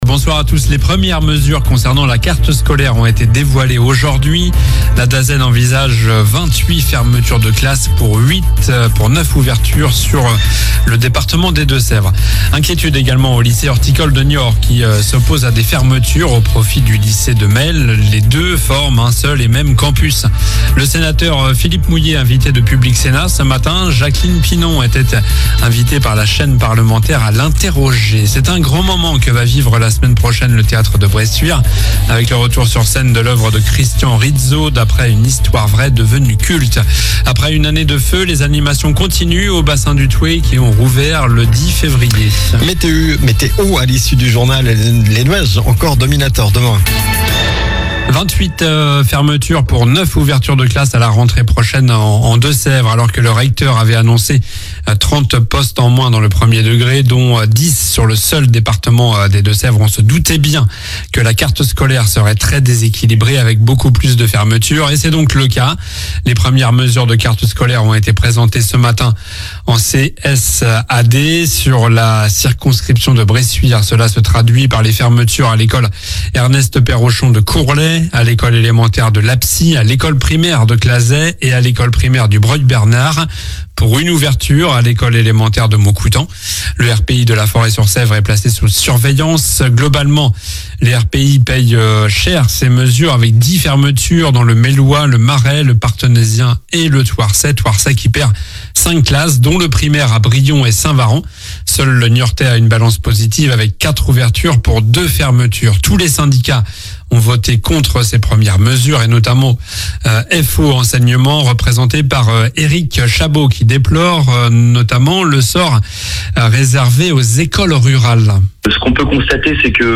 Journal du mercredi 12 février (soir)